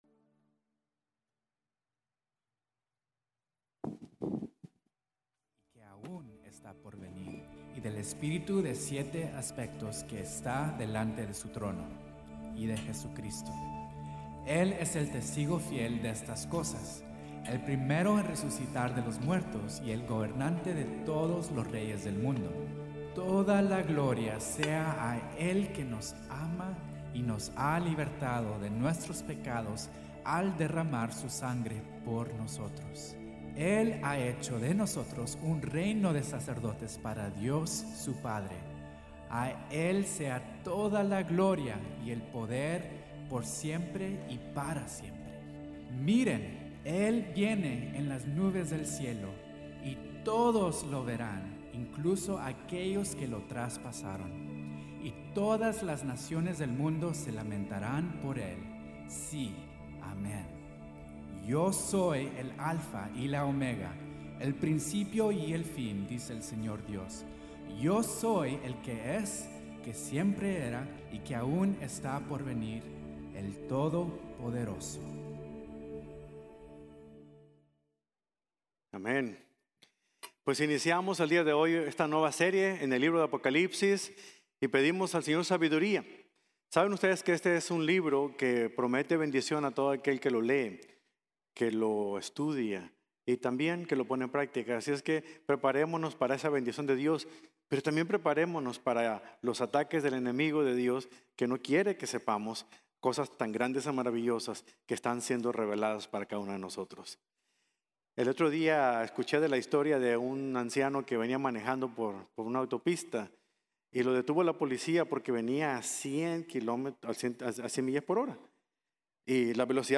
El Mensajero en Su Majestad | Sermon | Grace Bible Church